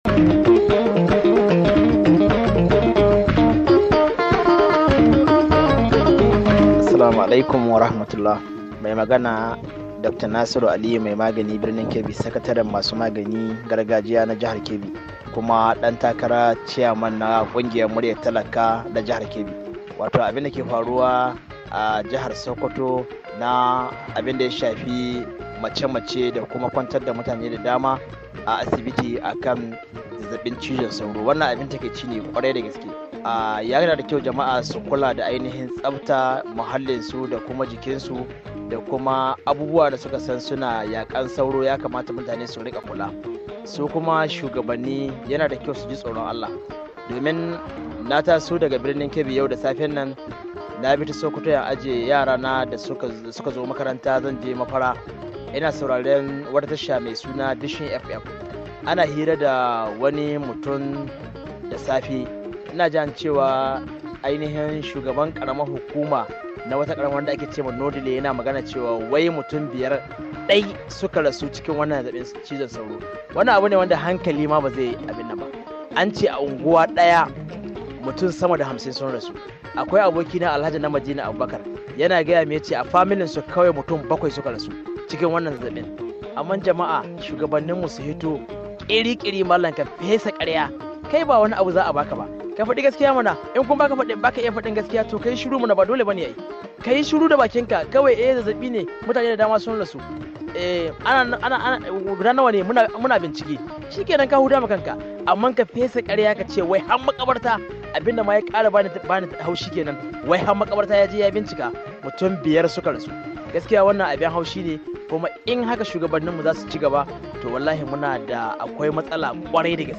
Wasu daga cikin Membobin Muryar Talaka na Jihar Kano Suke gabatar da shawarwari da bayanai Kan Dandalin VOA.